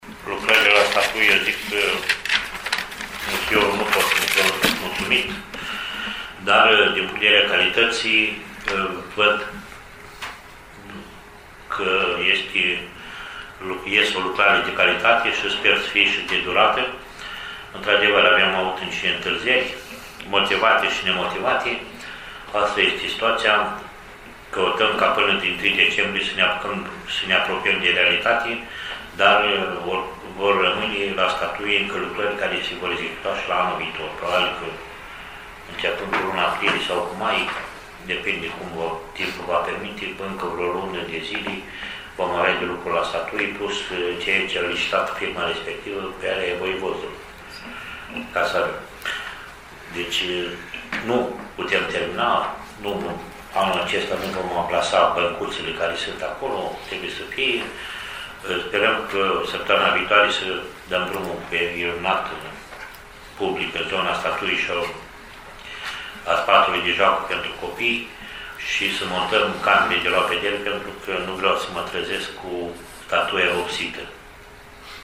Primarul municipiului Rădăuți, Nistor Tătar, a prezentat, în conferința de presă de joi, situația lucrărilor de reabilitare a statuii ecvestre a lui Bogdan I, aflată în centrul municipiului Rădăuți.